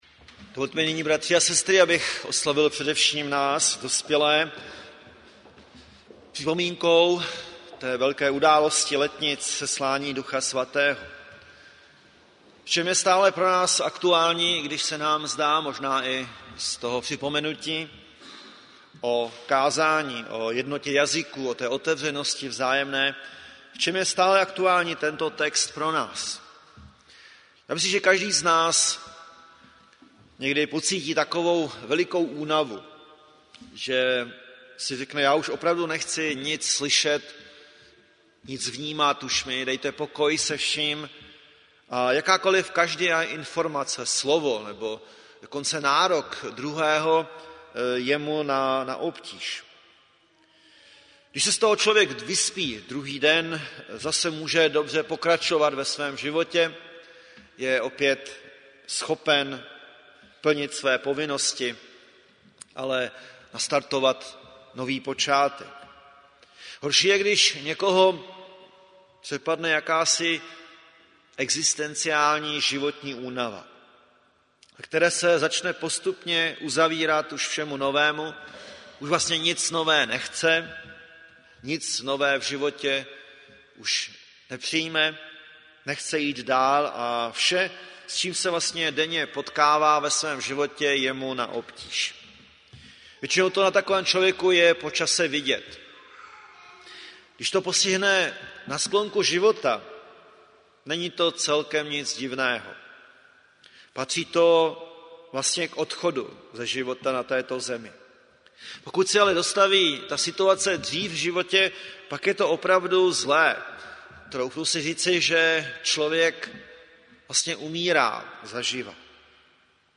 Rodinná neděle
záznam kázání